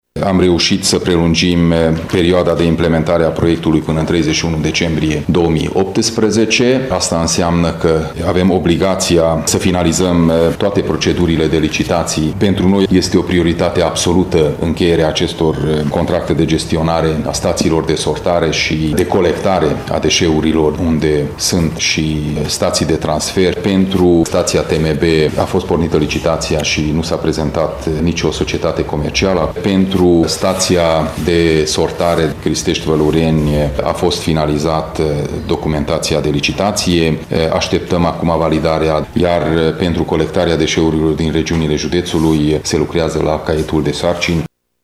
Este vorba de întârzierea încheierii contractelor de gestionare a staţiilor de sortare şi de colectare a deşeurilor din zonele judeţului în care există și staţii de transfer. Președintele CJ Mureș, Peter Ferenc a explicat că nu s-a prezentat nimeni la licitația pentru stația de tratare mecano-biologică a deșeurilor: